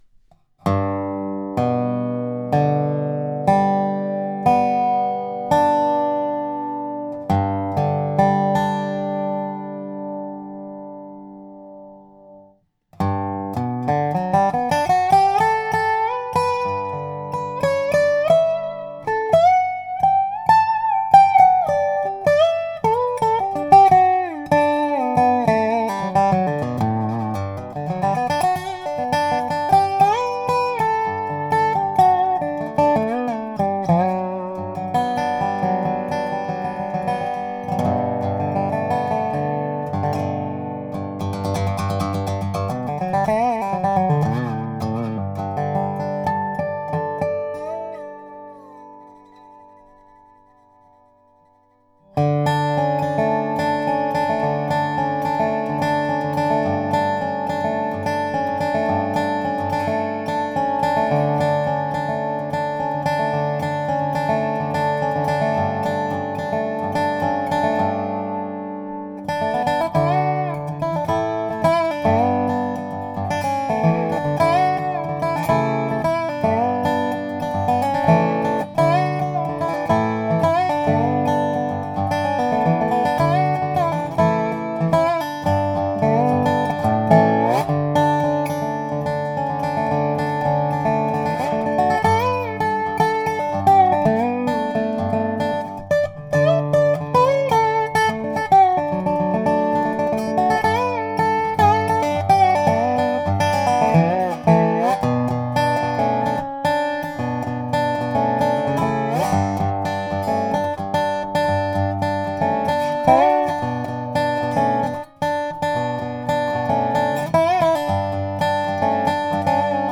Kvalitní rezonátor pro rezofonickou kytaru s pavoukem (spider) vyrobený z tvrzeného čistého hliníku tradiční metodou – ručním vykružováním.